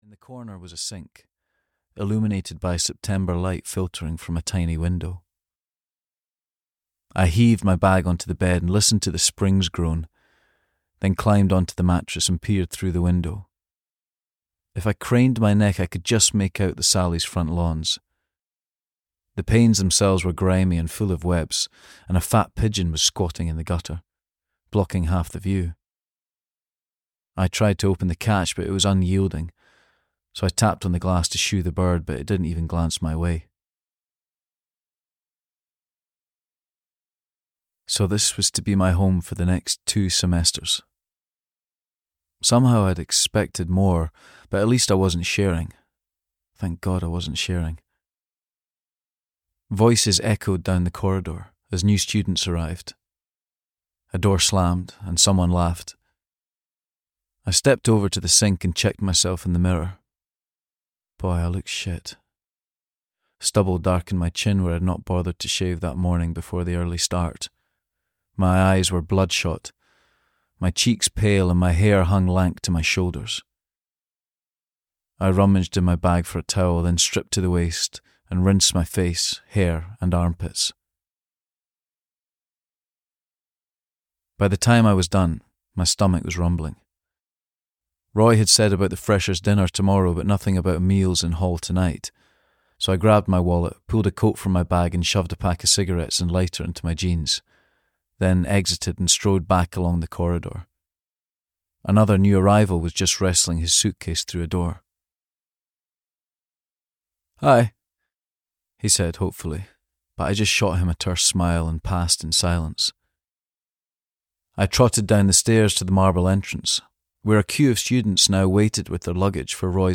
When We Were Killers (EN) audiokniha
Ukázka z knihy